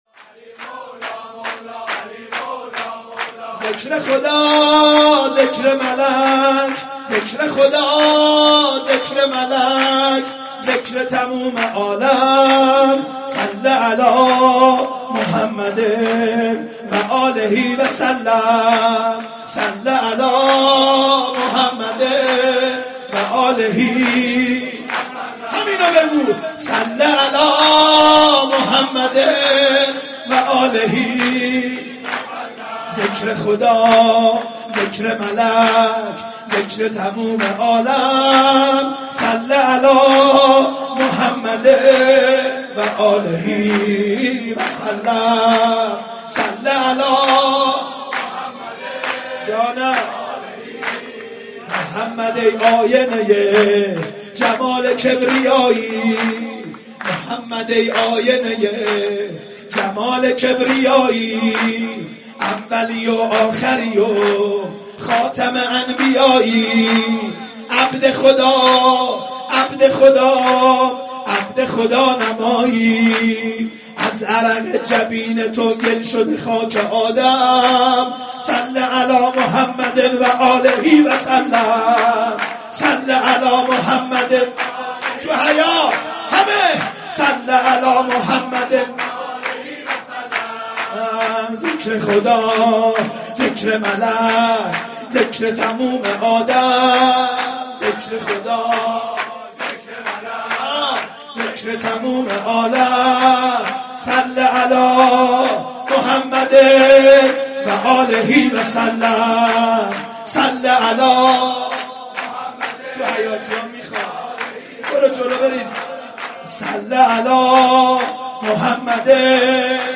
جشن عید مبعث/هیات آل یاسین قم
مدح حضرت احمد سلام الله علیه و آله
سرود